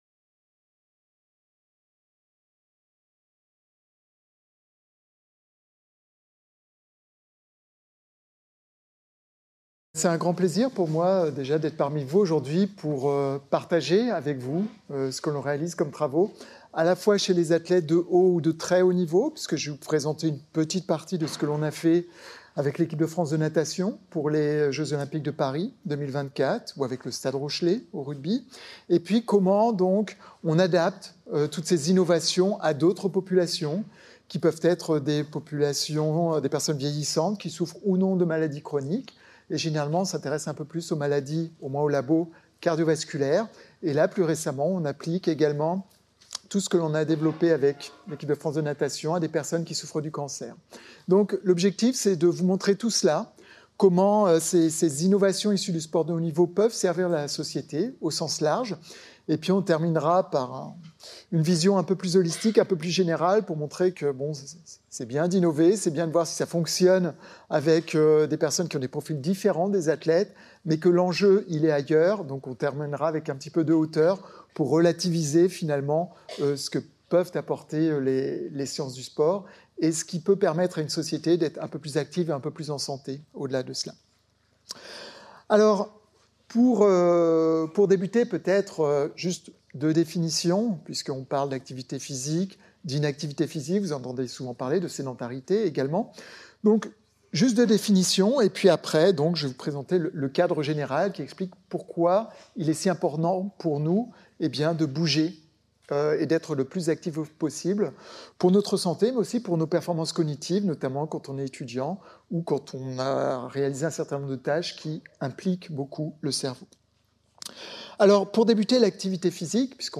Cette conférence présente la démarche innovante menée par l’Université de Poitiers pour accompagner l’équipe de France de natation dans sa préparation aux Jeux de Paris 2024.